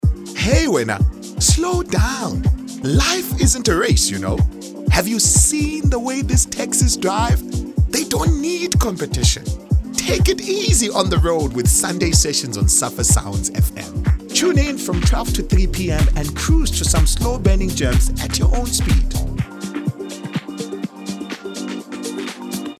South Africa
clear, crisp, precise, sharp
My demo reels